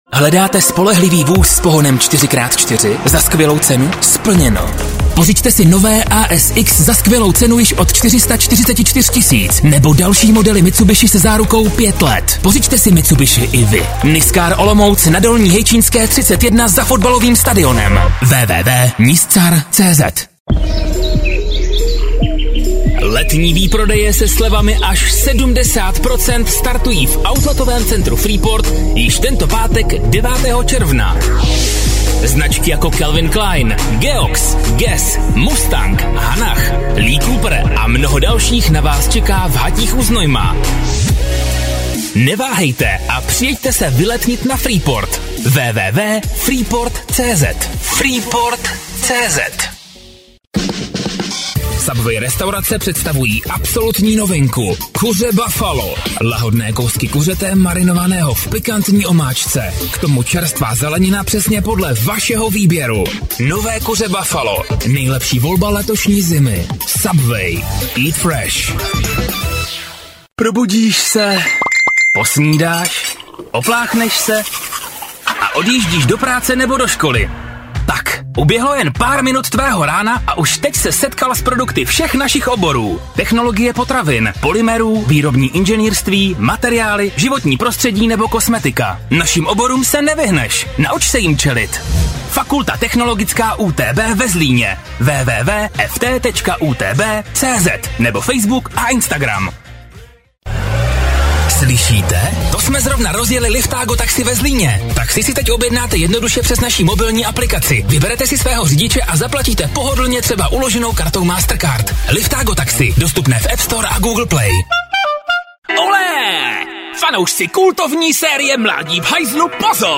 - namluvím pro Vás reklamní spot či voiceover do videa do 30s dle zadaných textových podkladů v profesionálním nahrávacím studiu
namluvil jsem již tisíce různých spotů a videí - voiceoverů (TV, rádio, internet, byl jsem i hlasem televize, atd.)